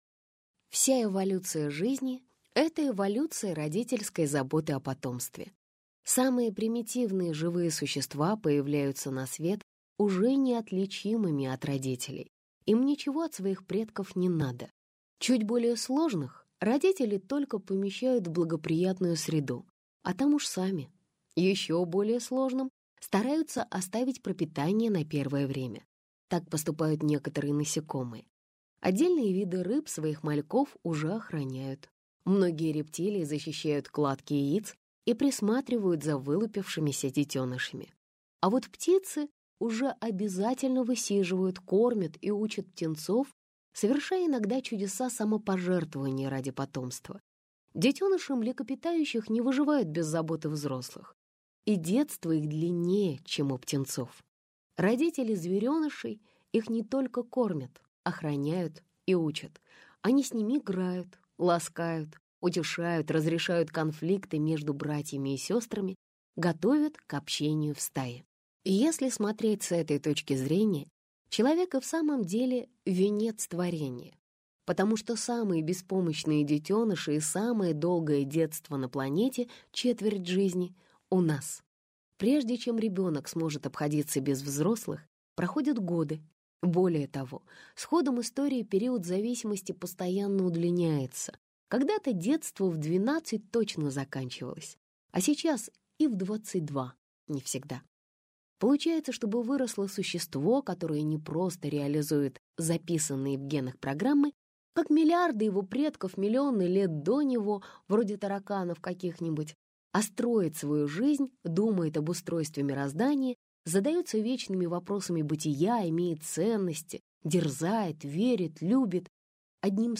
Аудиокнига Большая книга про вас и вашего ребенка - купить, скачать и слушать онлайн | КнигоПоиск